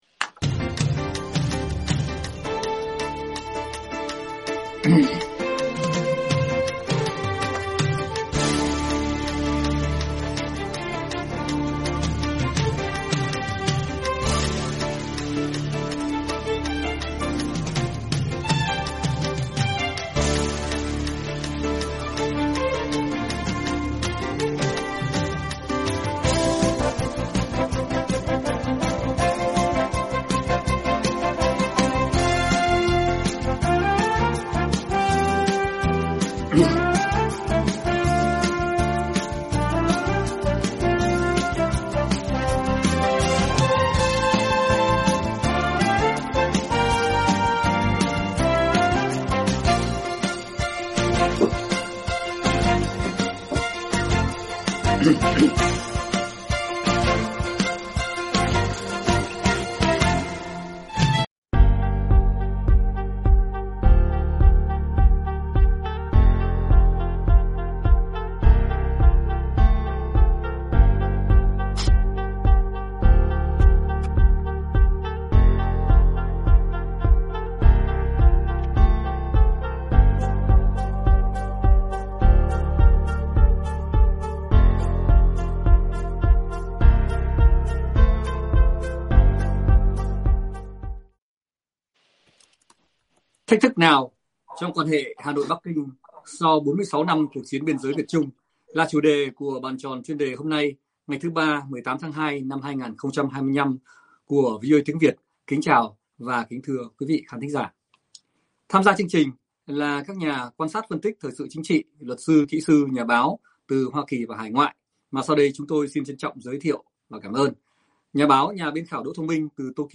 Bàn Tròn Chuyên Đề Thứ Ba ngày 18/02/2025 của VOA Tiếng Việt, với các diễn giả, khách mời là các nhà quan sát, phân tích thời sự chính trị, luật sư, kỹ sư và nhà báo tham gia thảo luận từ Hoa Kỳ và hải ngoại.